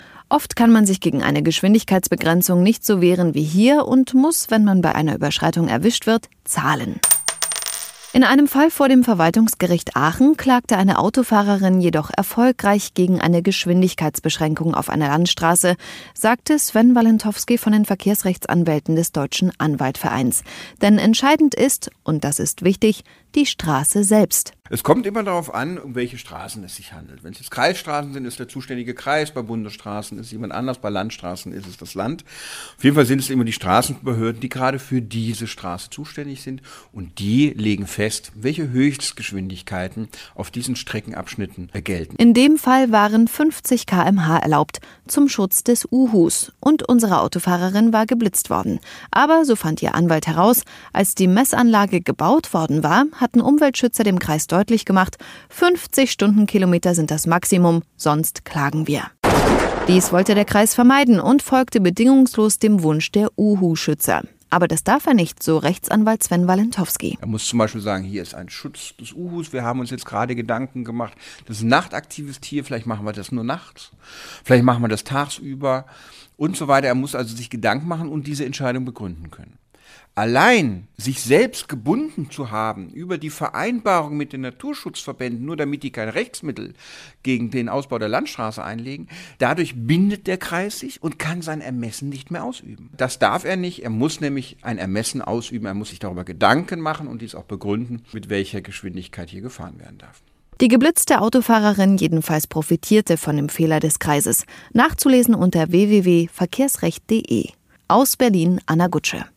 O-Töne / Radiobeiträge, , , ,